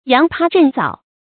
揚葩振藻 注音： ㄧㄤˊ ㄆㄚ ㄓㄣˋ ㄗㄠˇ 讀音讀法： 意思解釋： 葩：華美；藻：文采。